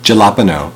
Ääntäminen
US Can: IPA : /ˌhæləˈpiːnoʊ/